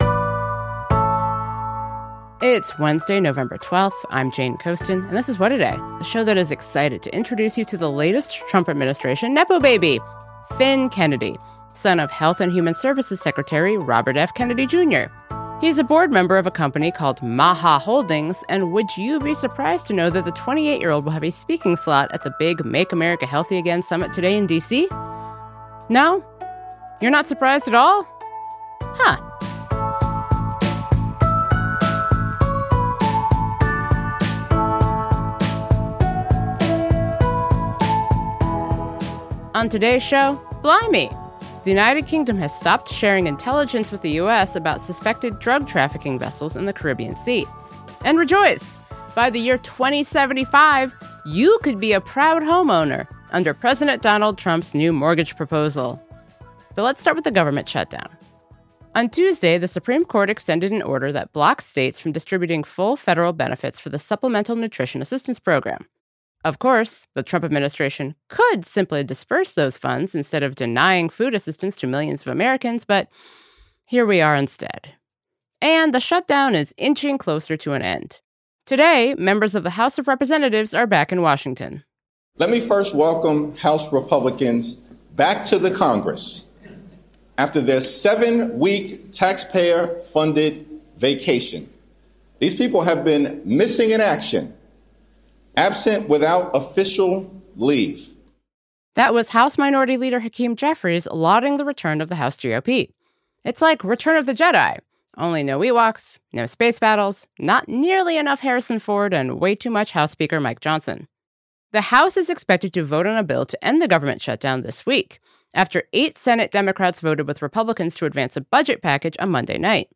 To discuss the potential end to the shutdown further, with no answers on healthcare, we spoke with Delaware Democratic Congresswoman Sarah McBride on Tuesday afternoon.